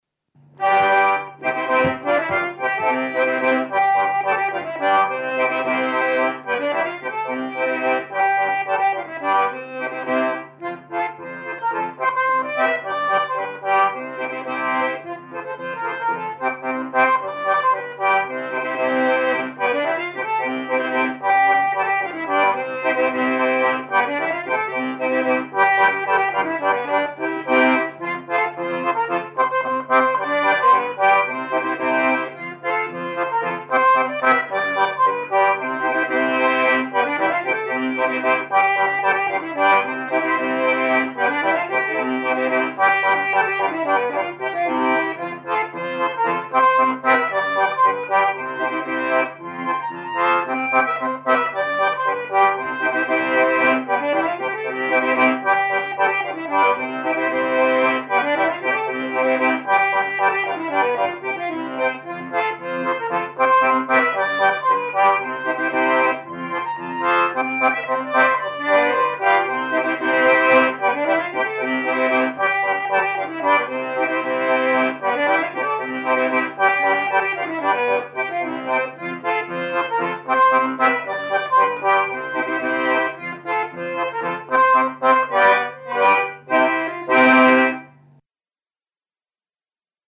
Krakovjak